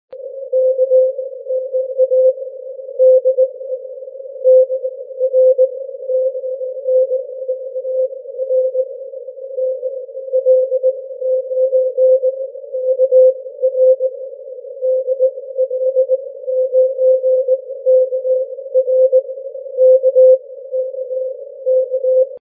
Sound from 12-13 november contest :
at 2500hz bw no filtering